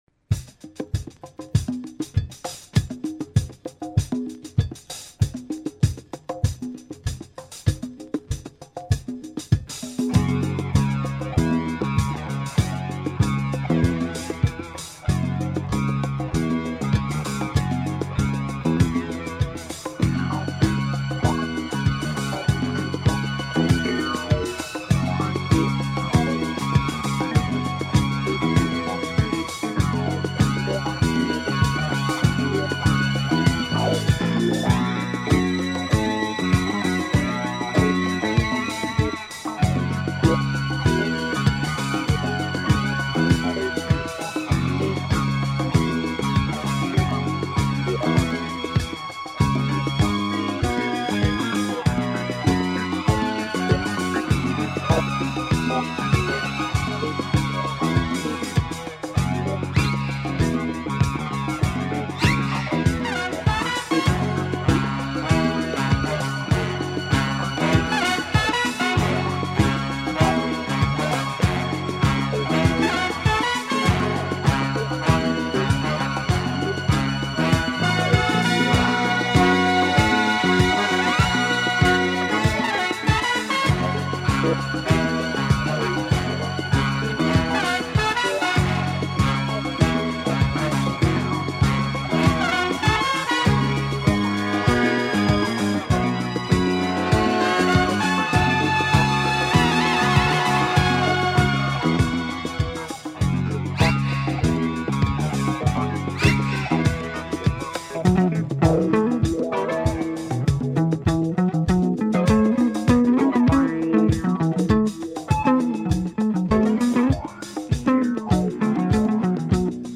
East German crime soundtrack. Funk and latin sounds.